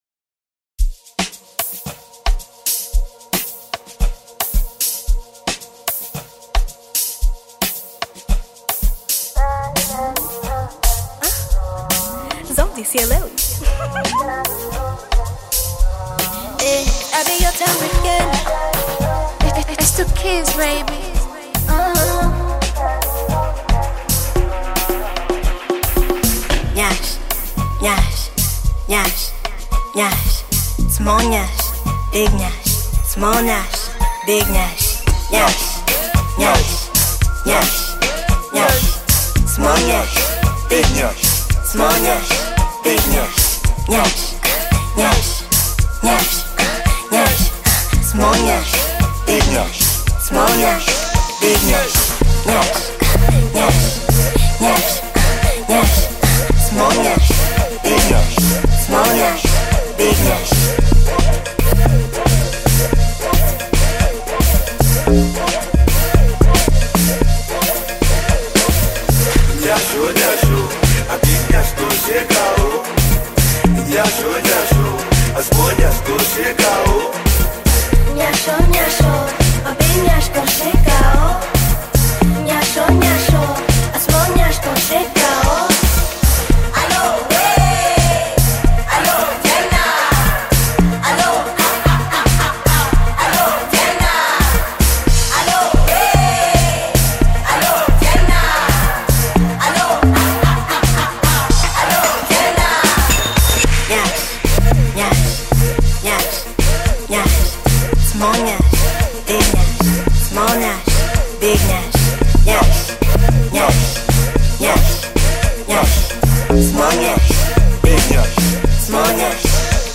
Bongo Flava song
This catchy new song